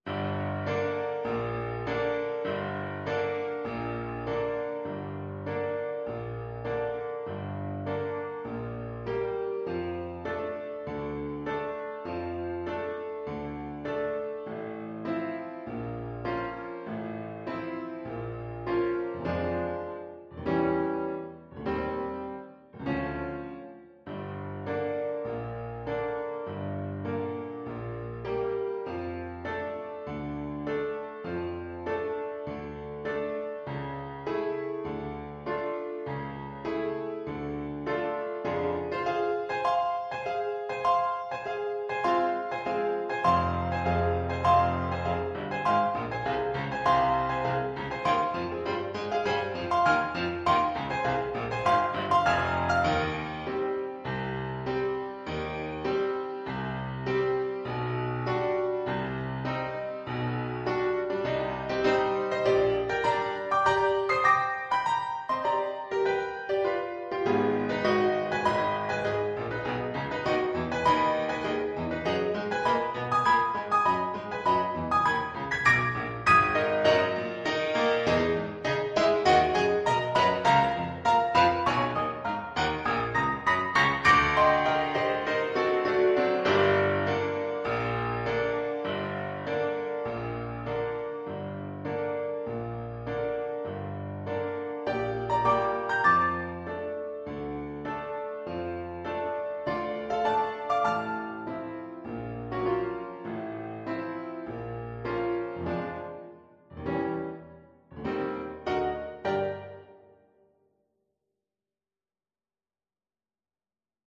4/4 (View more 4/4 Music)
Classical (View more Classical Viola Music)